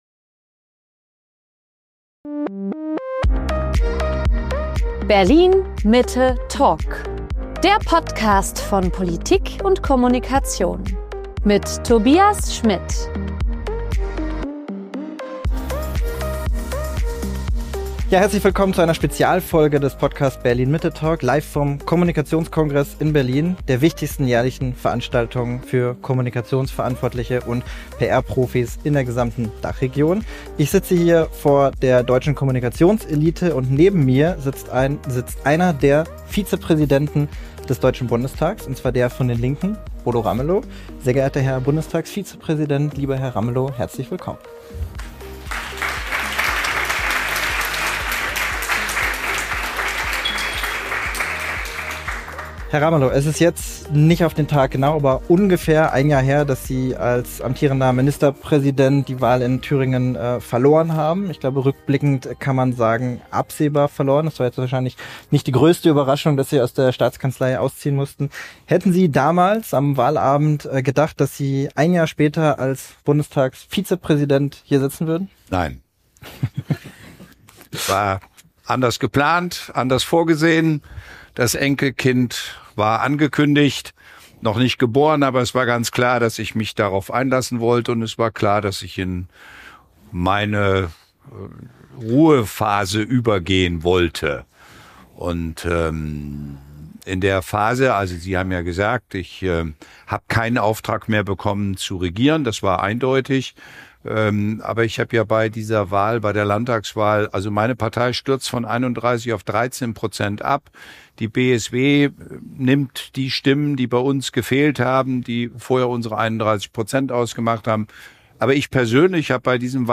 Beschreibung vor 6 Monaten Bodo Ramelow ist Vizepräsident des Deutschen Bundestages, ehemaliger Thüringischer Ministerpräsident und Mitglied bei den Linken. Im Podcast, live und vor Publikum beim Kommunikationskongress, sprach ich mit ihm über den Spagat seiner Partei, zwischen Fundamentalopposition und staatspolitischer Verantwortung.